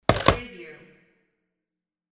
Phone Receiver Hang Up Wav Sound Effect #2
Description: The sound of hanging up a telephone receiver
Properties: 48.000 kHz 16-bit Stereo
Keywords: phone, receiver, telephone, hang, hanging, up, put, down
phone-hang-up-preview-2.mp3